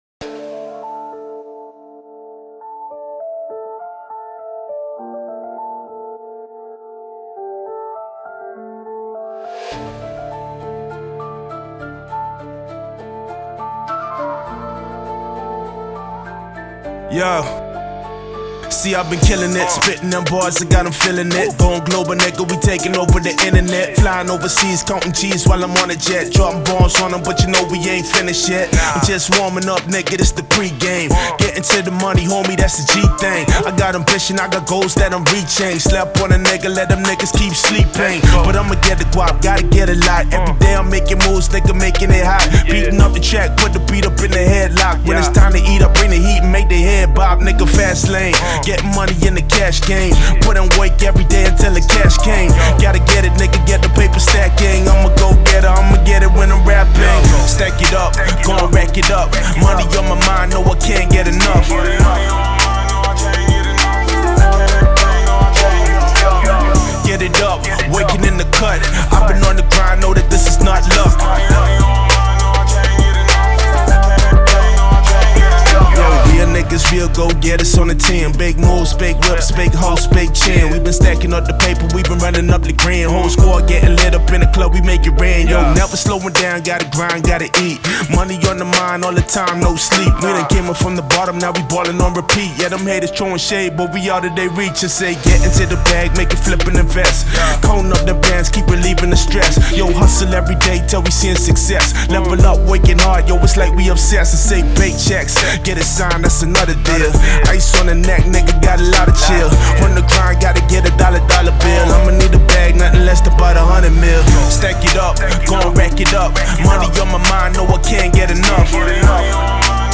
This is a mp3 acapella file and does not include stems